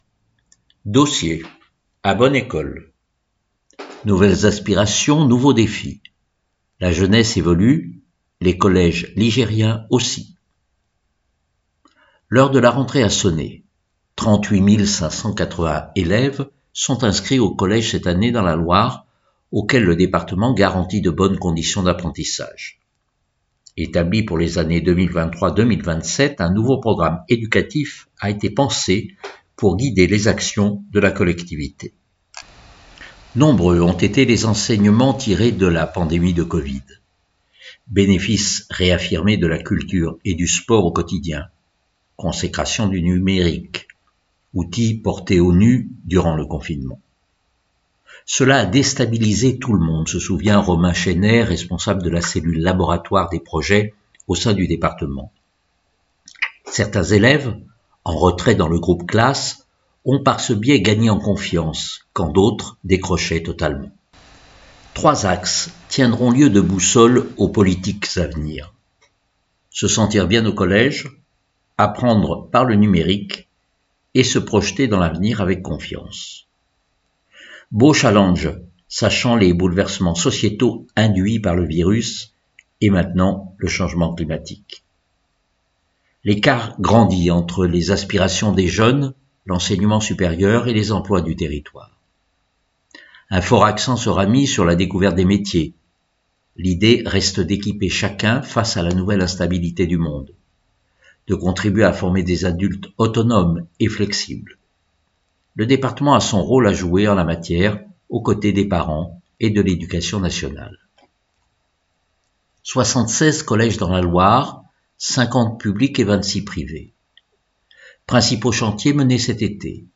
Loire Magazine n°157 version sonore